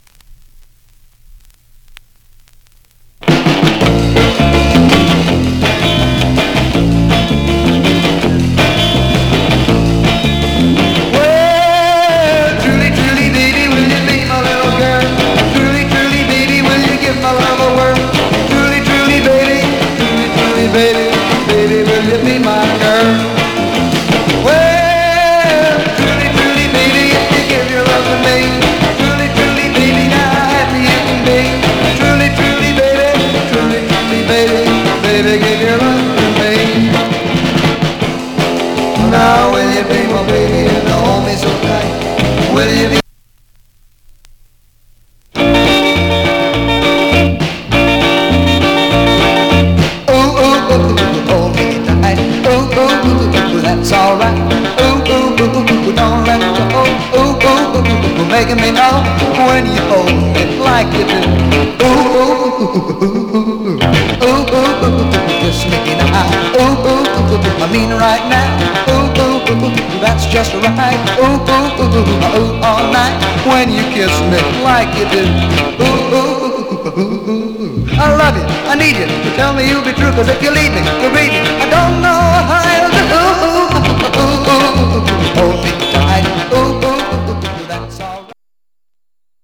Surface noise/wear
Mono
Teen